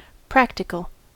practical: Wikimedia Commons US English Pronunciations
En-us-practical.WAV